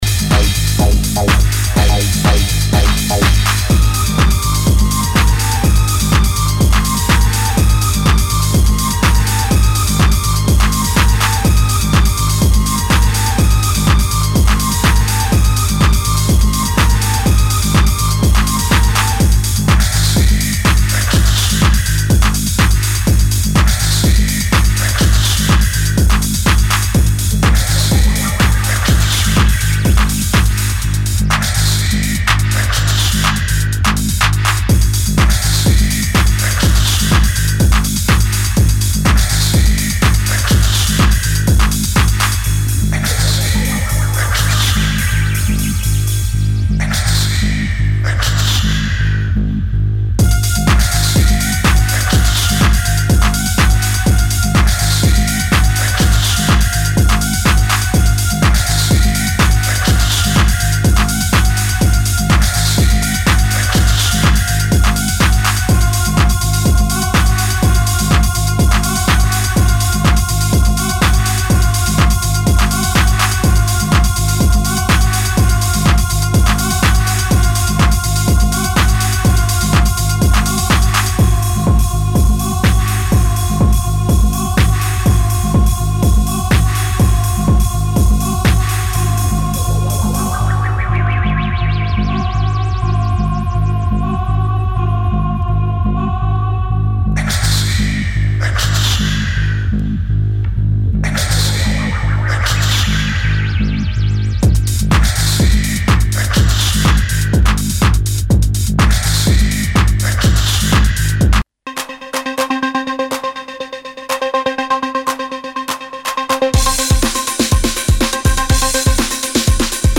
Techno-Electro